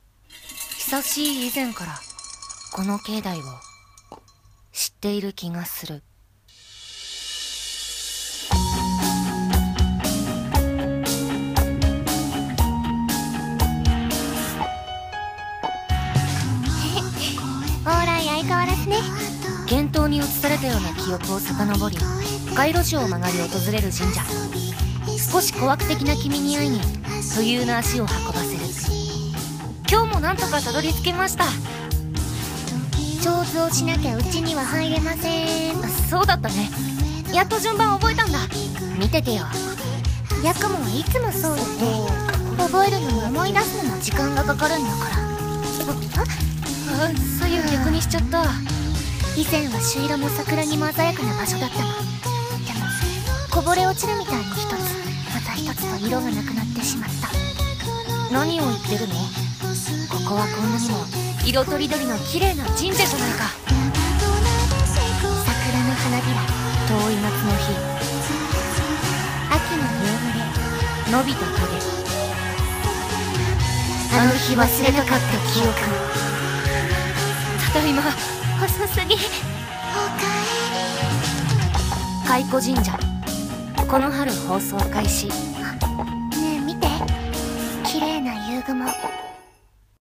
CM風声劇｢懐古神社｣お手本